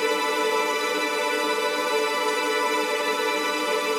GS_TremString-D6+9.wav